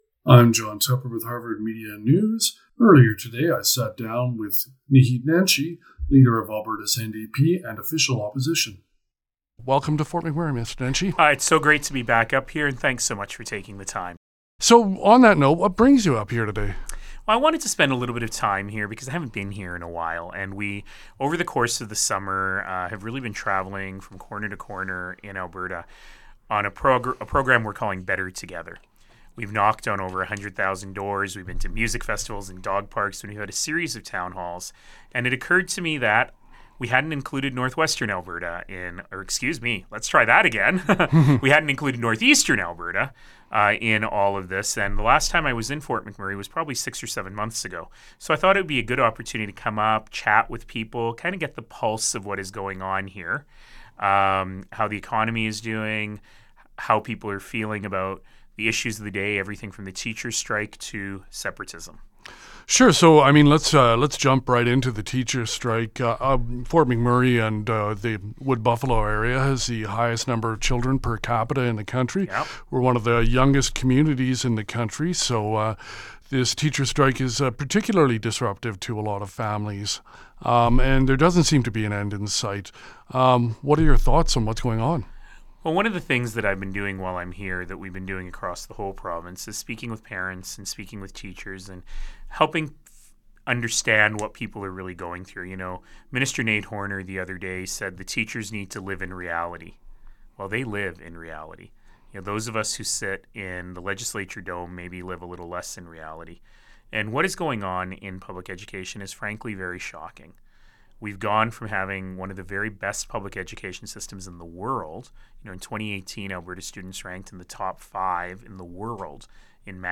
Naheed Neshi visits Harvard Media News for exclusive interview